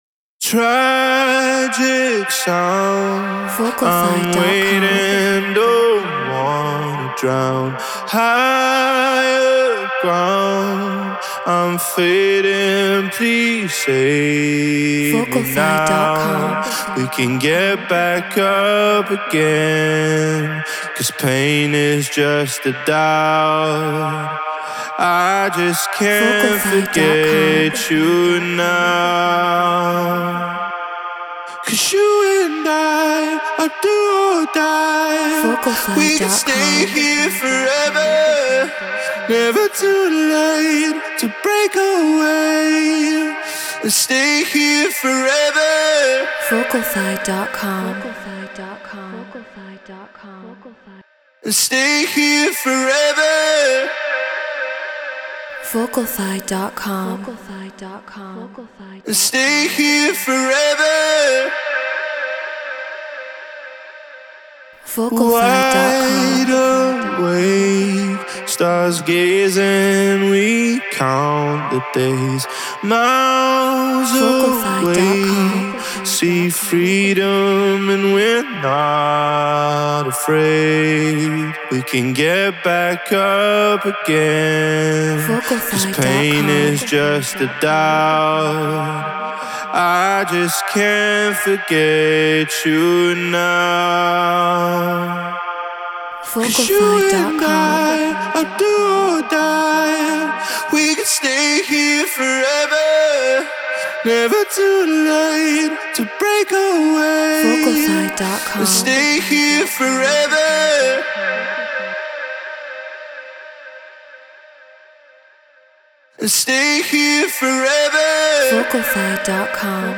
Progressive House 126 BPM D#maj
Human-Made